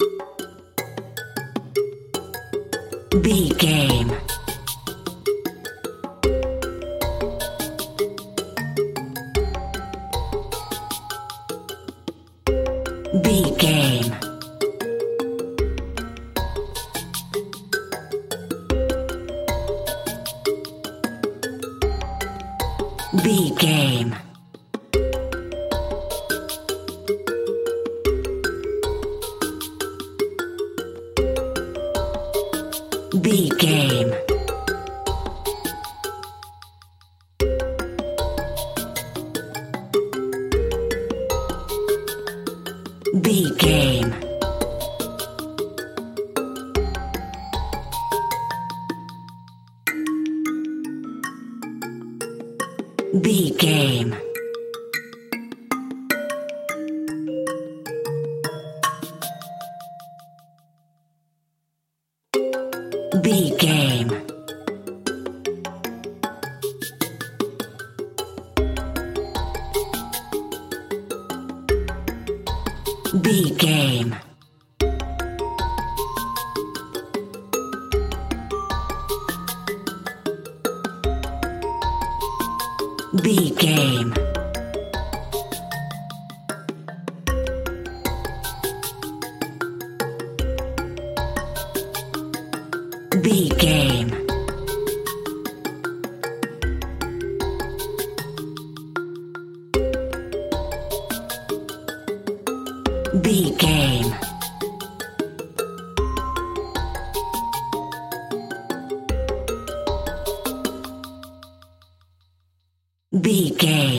Aeolian/Minor
bongos
congas
Claves
cabasa
hypnotic
medium tempo
ominous
mellow